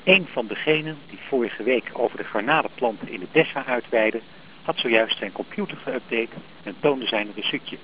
Dicteezin.